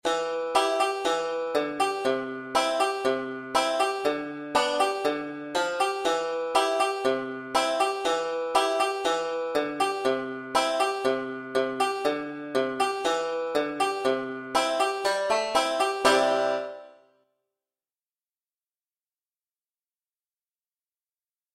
Beginning Five-String Folk Banjo - Part Nine - Standard (C) Tuning (DBGCg)